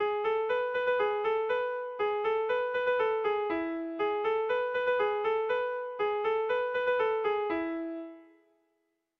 Haurrentzakoa
ABAB